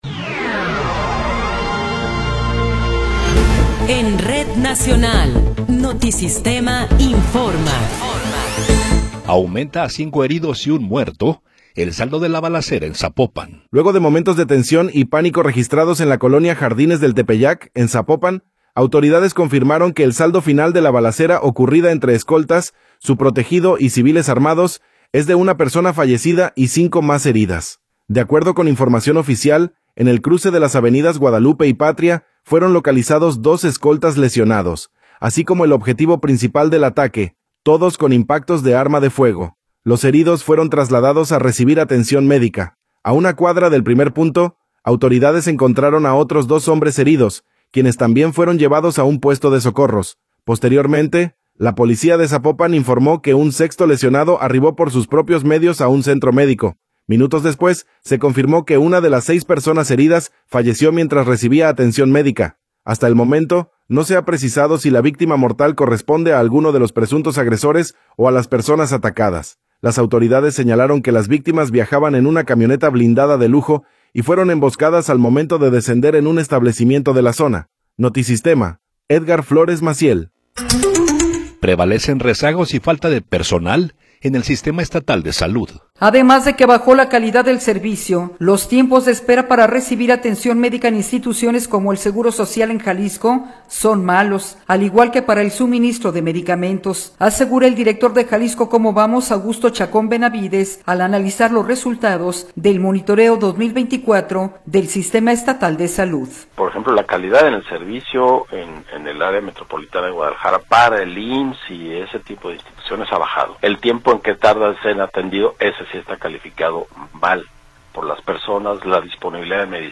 Noticiero 15 hrs. – 27 de Enero de 2026